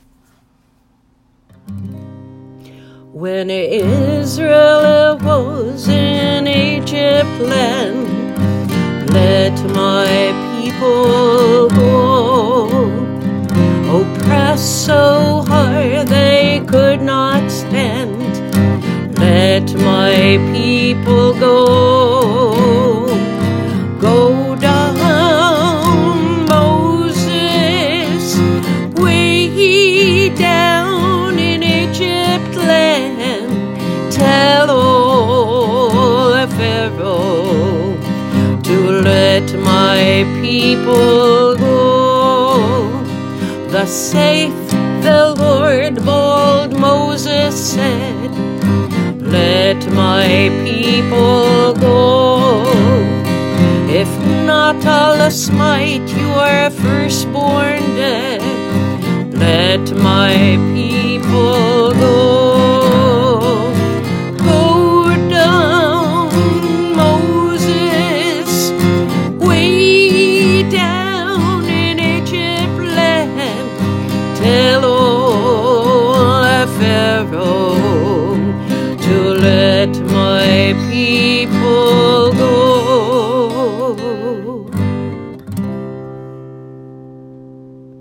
2-let-my-people-go-adults-pg-23-a-family-haggadah.m4a